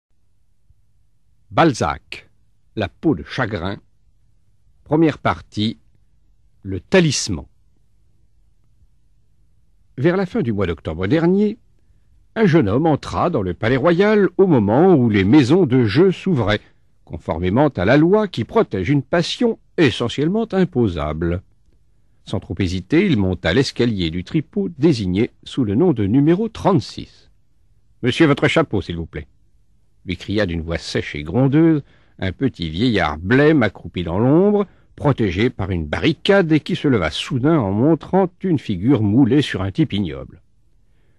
Click for an excerpt - La peau de chagrin de Honoré de Balzac